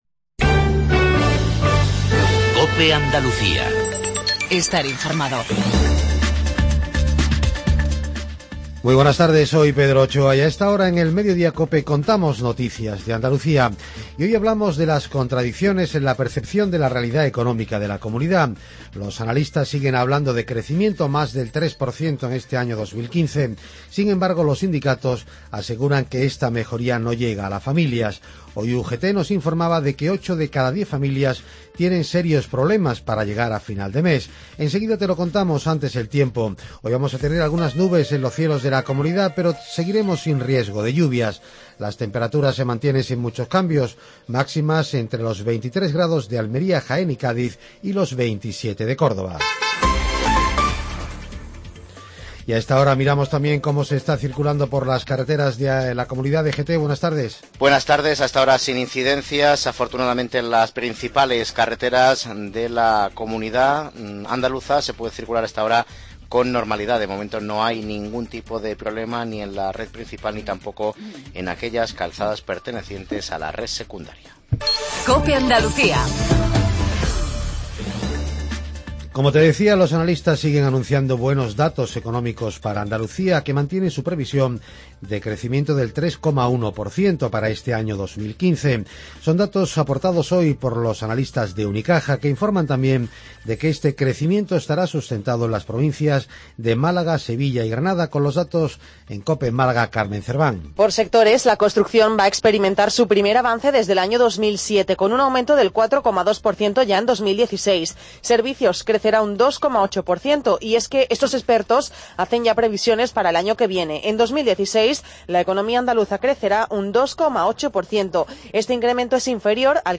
INFORMATIVO REGIONAL/LOCAL MEDIODIA